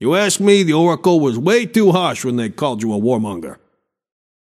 Shopkeeper voice line - You ask me, the Oracle was way too harsh when they called you a warmonger.